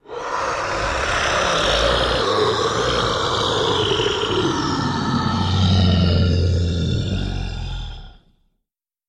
Звуки дракона
Звук рычания дракона в состоянии покоя